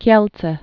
(kyĕltsĕ)